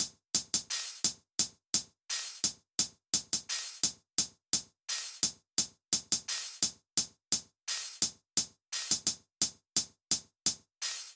空袭警报和二战螺旋桨飞机
描述：二战期间，空袭警报和螺旋桨飞机经过的声景设计。
标签： 二战 战争 螺旋桨 轰炸机 炸弹 飞机 夜晚 警报
声道立体声